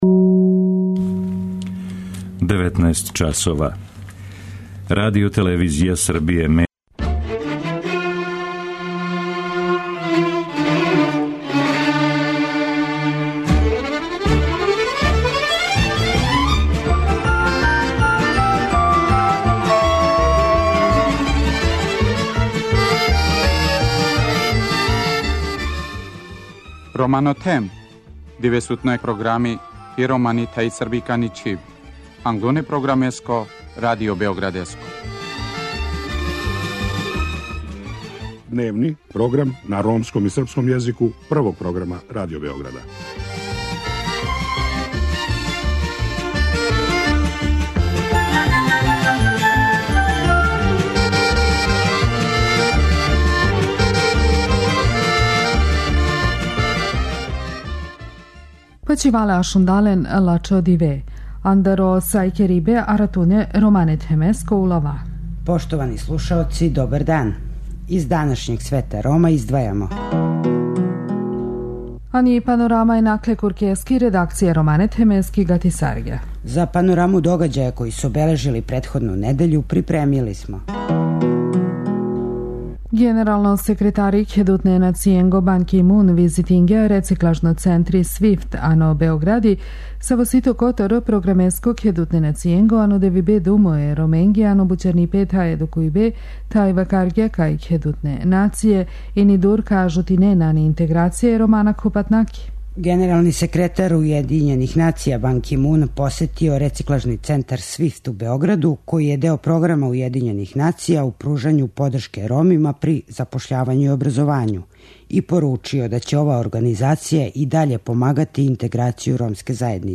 У нашим недељним емисијама слушаћете по једну ромску причу.